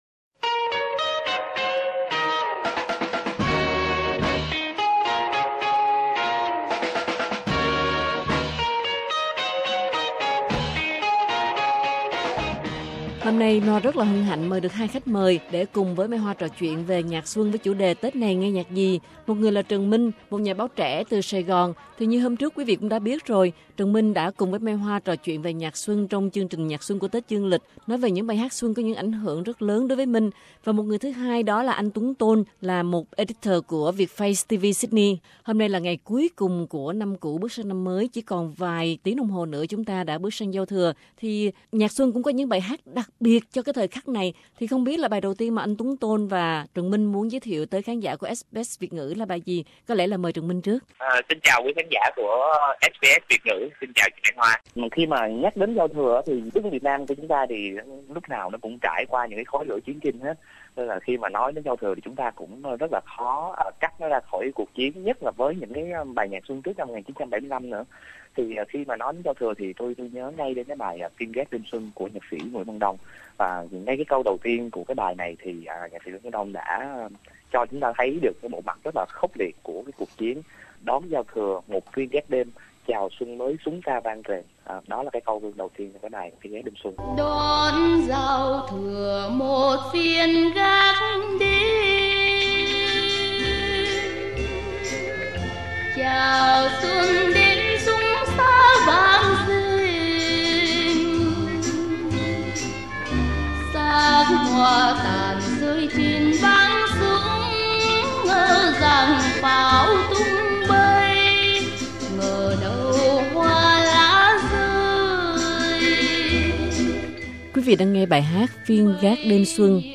Mừng xuân, SBS Việt ngữ đem đến cho quý vị một chương trình nhạc với những bài hát xuân đã ghi sâu trong lòng người Việt từ nhiều thế hệ.